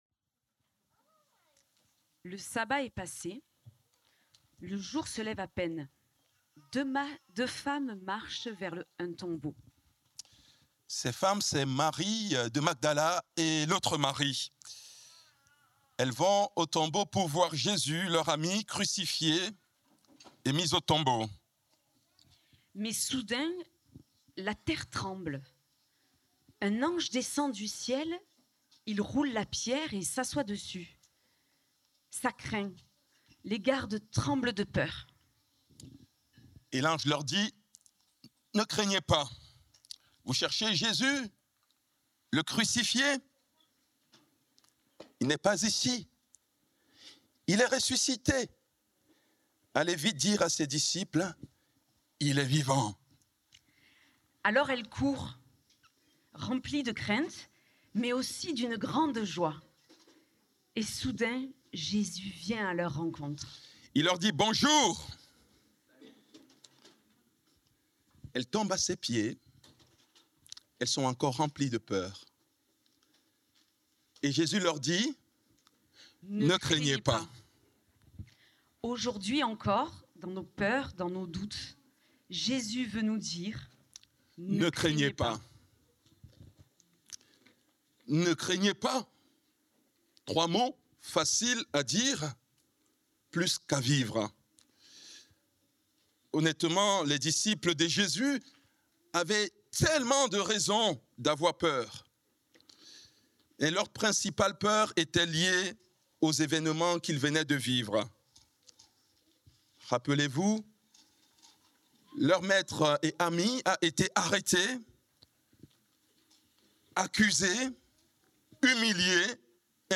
Culte du 05 avril 2026, prédication